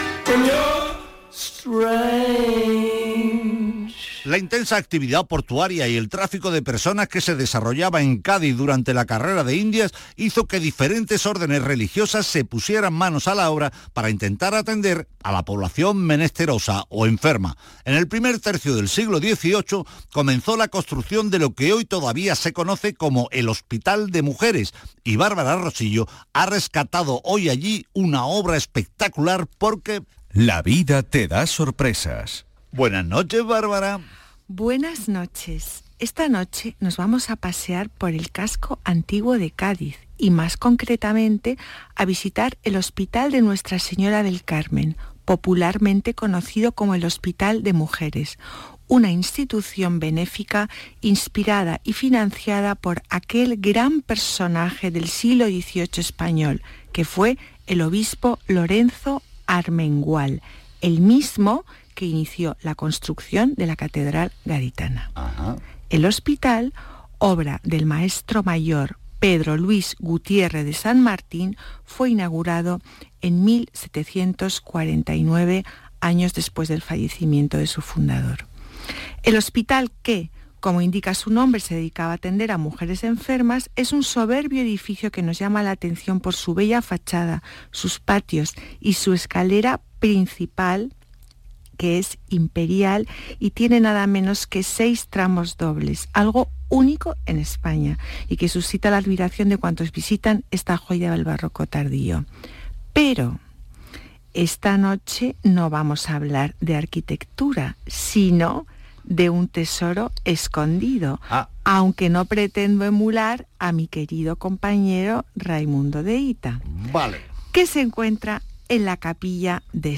Aquí os dejo mi intervención en el programa de Radio Andalucía Información, «Patrimonio andaluz» del día 18/05/2023